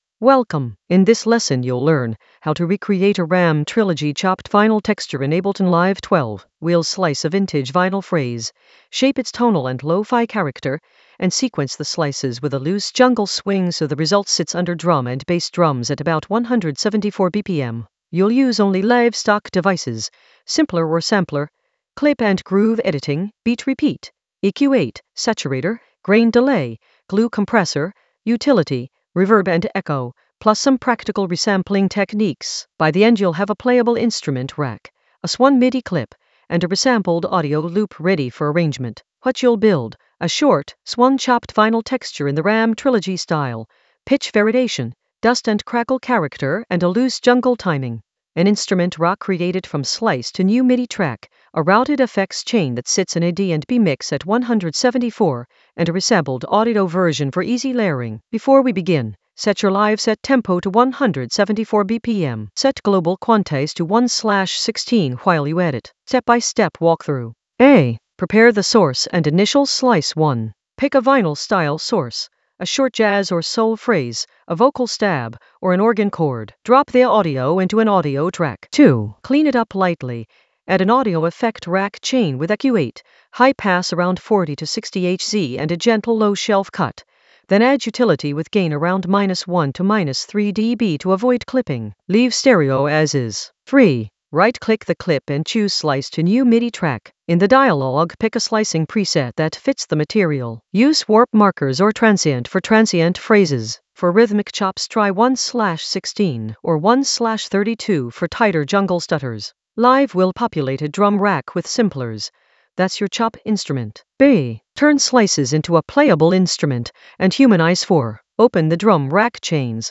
An AI-generated intermediate Ableton lesson focused on Ram Trilogy chopped-vinyl texture: carve and arrange in Ableton Live 12 with jungle swing in the Atmospheres area of drum and bass production.
Narrated lesson audio
The voice track includes the tutorial plus extra teacher commentary.